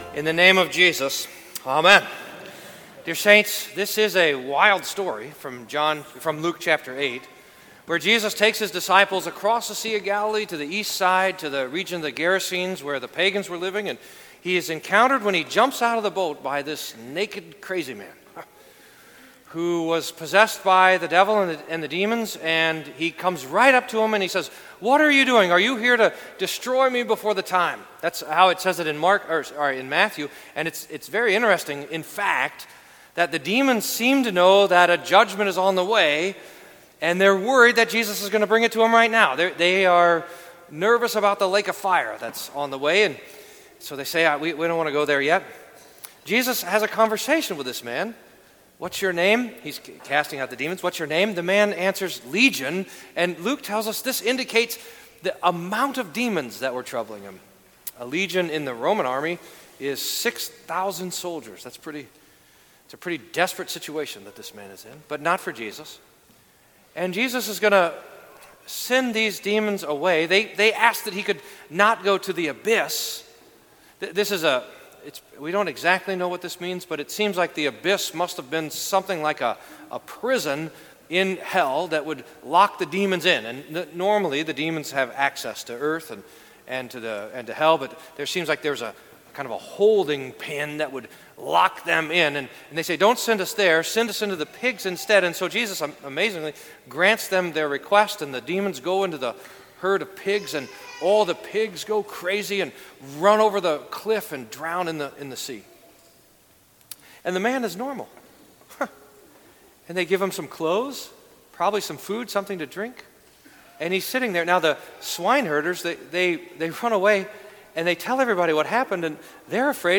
Sermon for Second Sunday after Pentecost